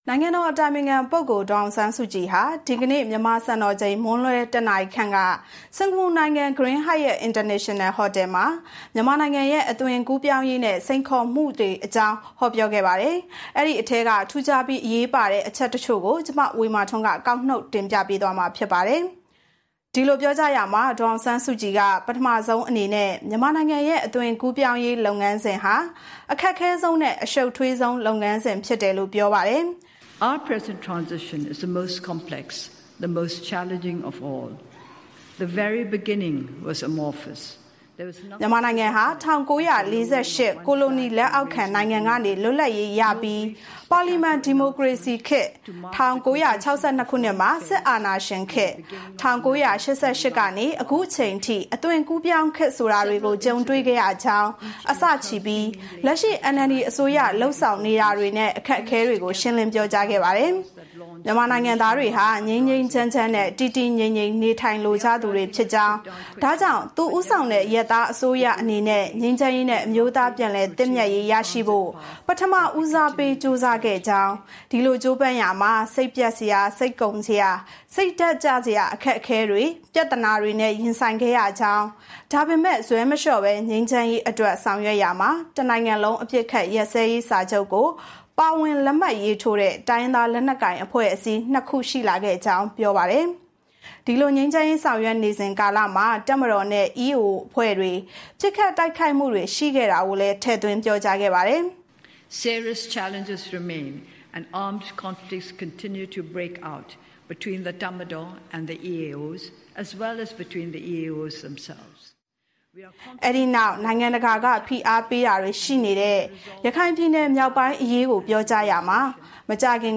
နိုင်ငံတော်အတိုင်ပင်ခံပုဂ္ဂိုလ် ဒေါ်အောင်ဆန်းစုကြည်ဟာ မြန်မာနိုင်ငံ အသွင်ကူးပြောင်းရေးနဲ့ စိန်ခေါ်ချက်တွေအကြောင်းကို စင်္ကာပူနိုင်ငံ ဂရင်းဟိုက်ယက်အင်တာနေရှင်နယ် ဟိုတယ်မှာ ဟောပြောခဲ့ပါတယ်။